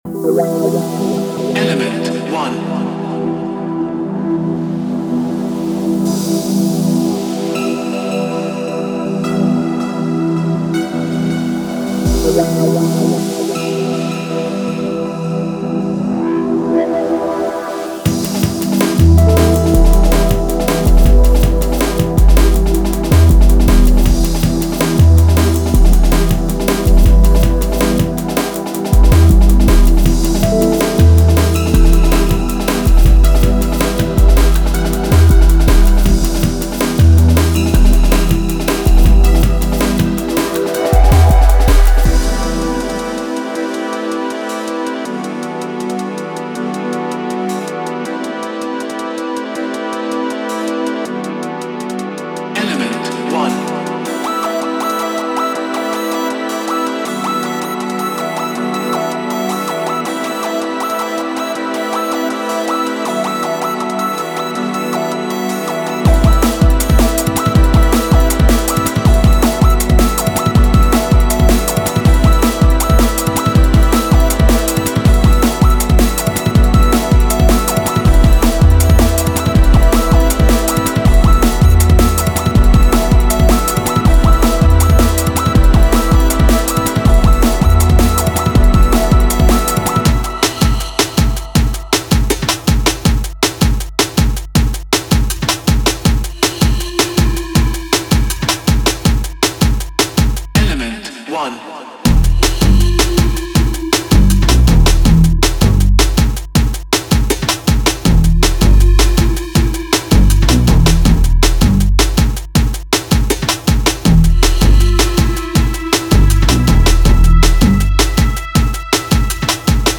Genre:Jungle